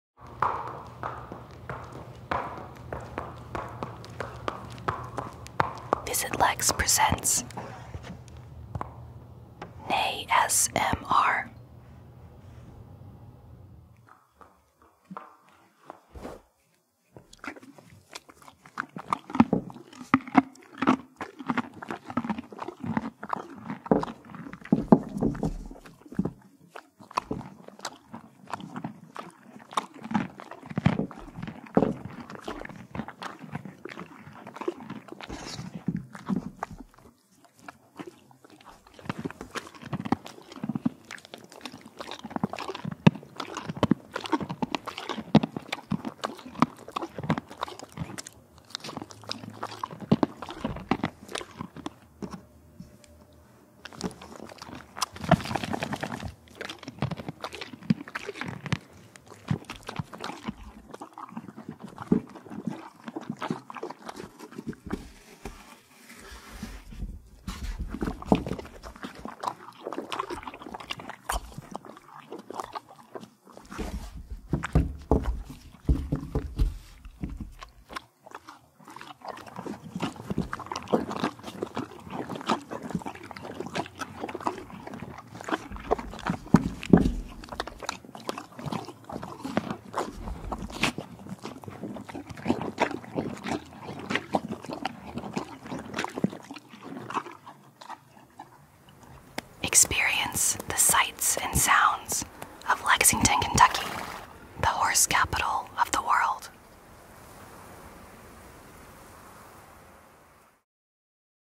NEIGH-SMR (aka Horse Eating ASMR) (192kbit_AAC).m4a